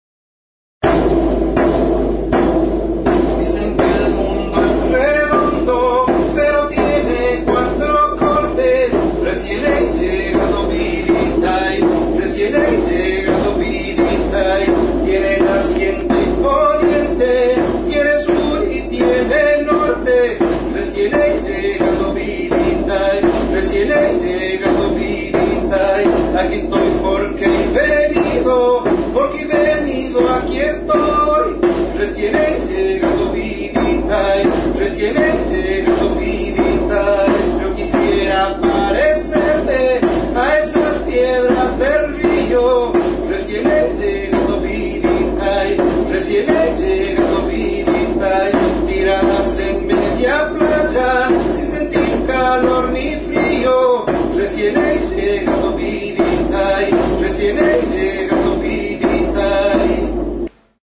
Recorded with this music instrument.
CAJA
Membranophones -> Beaten -> Stick-beaten drums
Larruzko bi mintz dituen danbor zilindrikoa da.